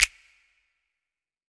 YM - Reverb Snap 6.wav